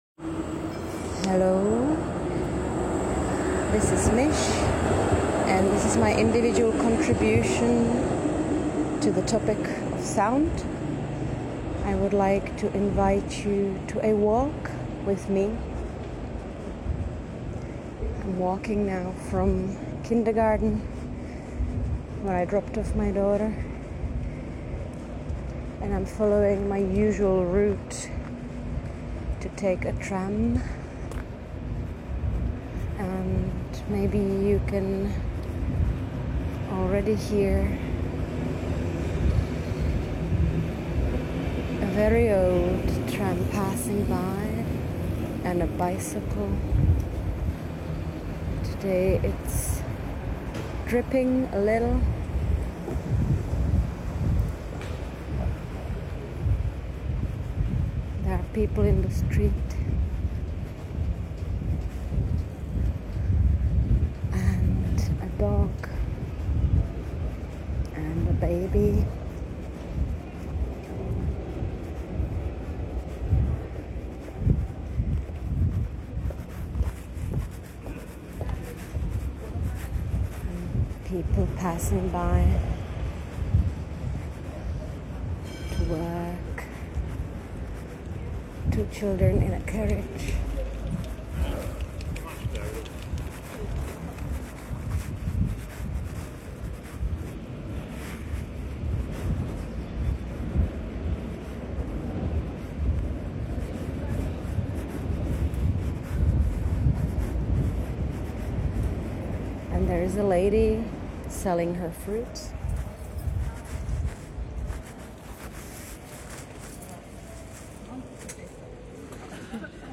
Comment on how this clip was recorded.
Audio walk through the city